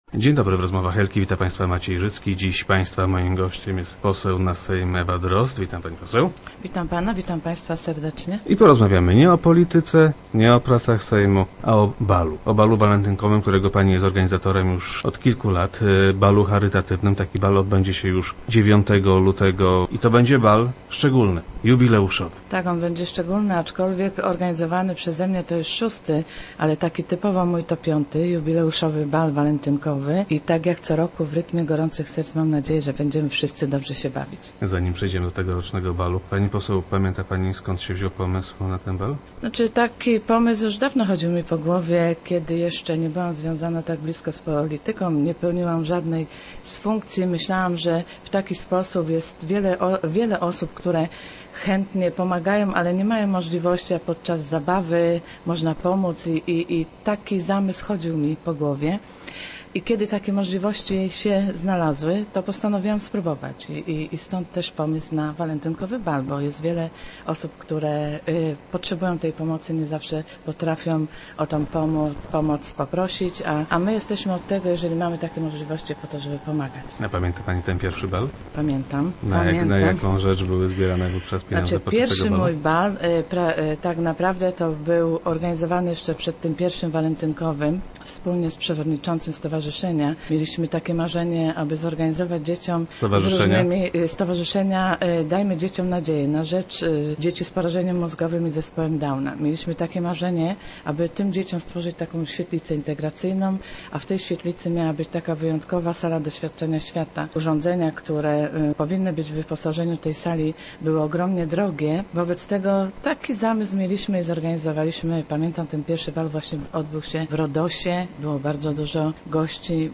- Ujął mnie ojciec tych dziewczynek – powiedziała posłanka, która była gościem poniedziałkowych Rozmów Elki.
Zapowiedział on również swój udział w balu - mówiła w radiowym studio Ewa Drozd.